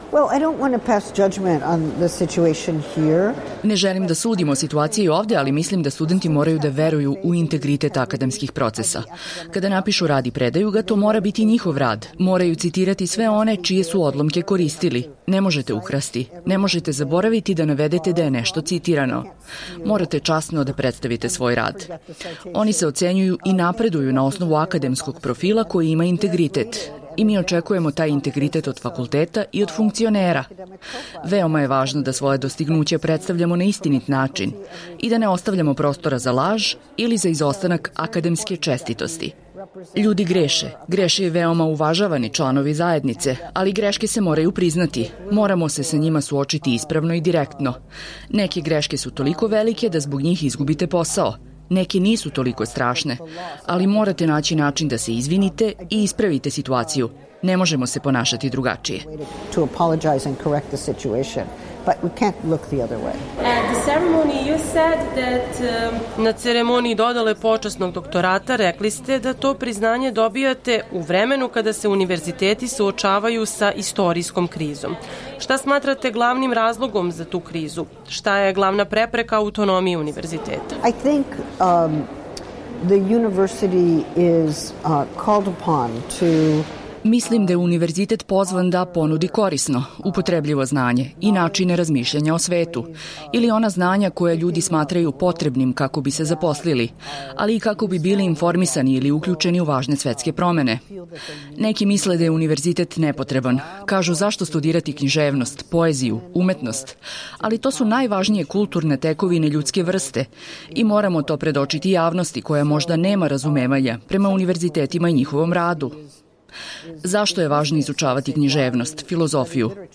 Intervju nedelje: Džudit Batler